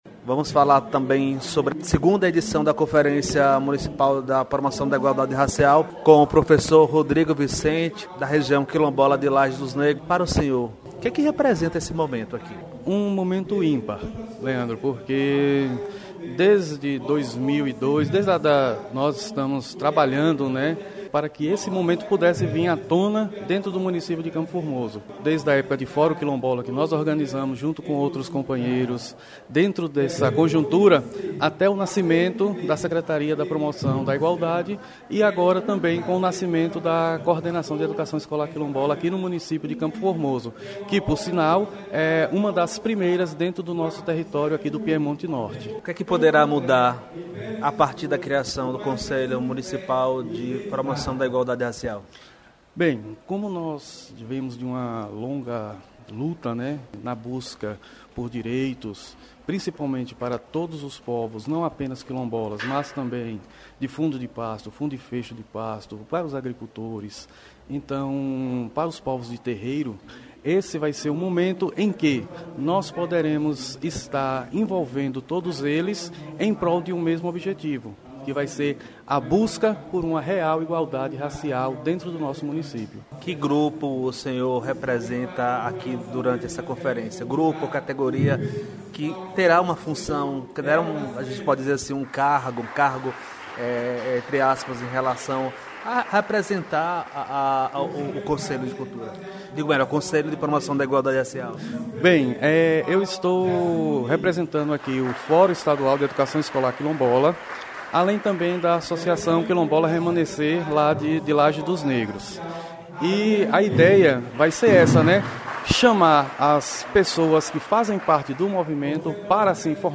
Professores representantes de Lages dos Negros falam da importância da Conferência municipal da promoção de Igualdade Racial